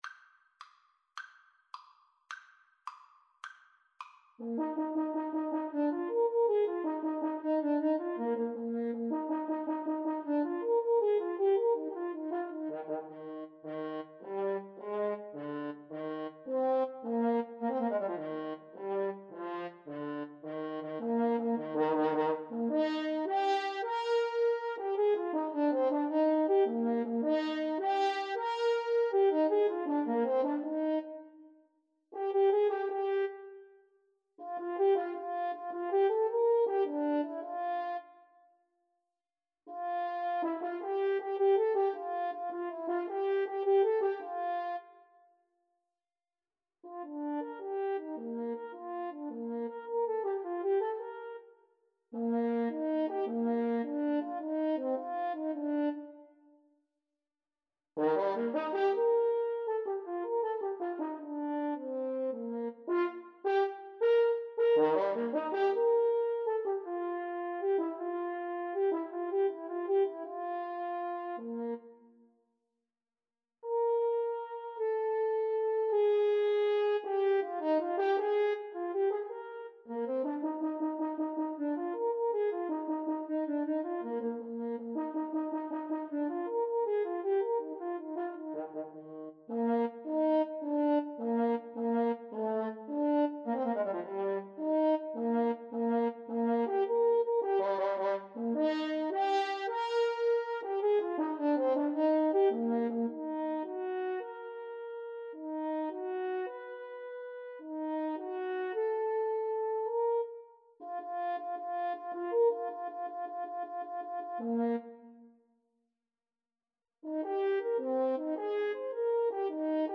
Free Sheet music for French Horn Duet
Eb major (Sounding Pitch) Bb major (French Horn in F) (View more Eb major Music for French Horn Duet )
6/8 (View more 6/8 Music)
.=106 Allegro vivace (View more music marked Allegro)
French Horn Duet  (View more Advanced French Horn Duet Music)
Classical (View more Classical French Horn Duet Music)